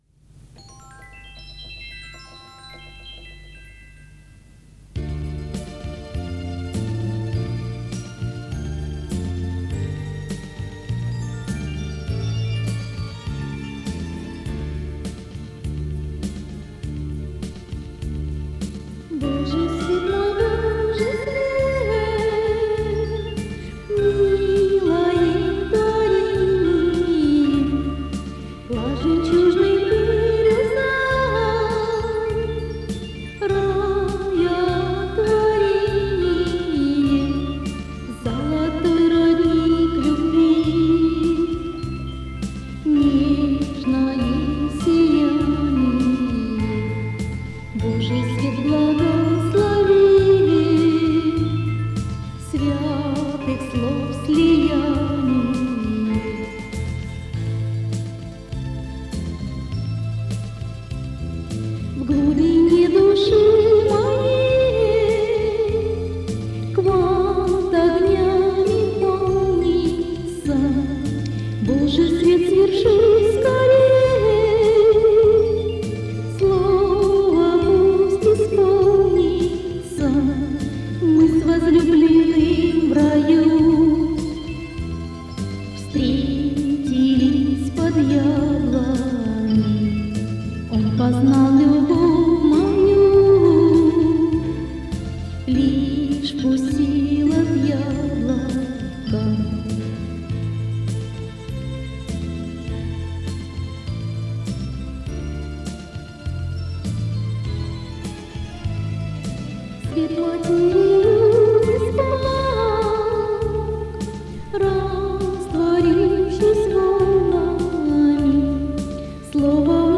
Мистическая музыка Духовная музыка